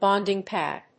bonding+pad.mp3